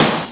• Studio Snare Drum G# Key 408.wav
Royality free steel snare drum sound tuned to the G# note. Loudest frequency: 3968Hz
studio-snare-drum-g-sharp-key-408-8SU.wav